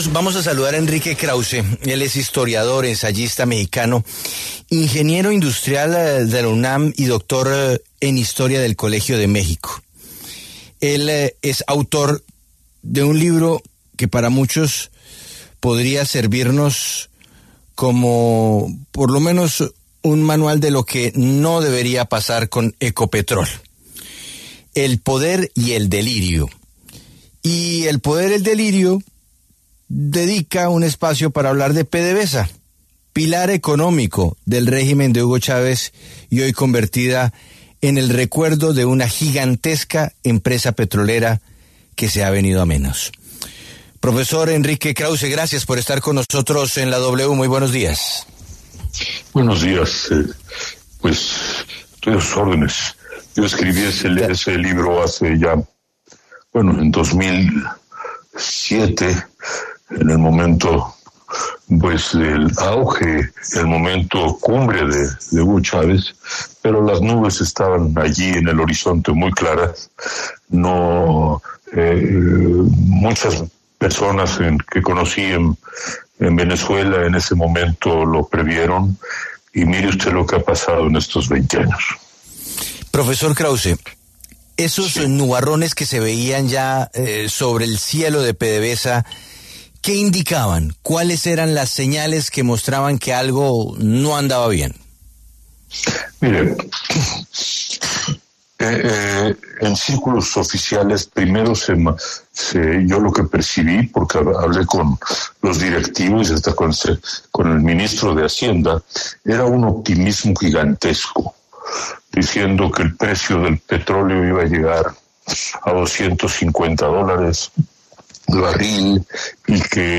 El historiador y ensayista mexicano, Enrique Krauze, pasó por los micrófonos de La W para hablar sobre los acontecimientos que llevaron al declive de la petrolera venezolana PDVSA, por motivo de los recientes sucesos en Ecopetrol.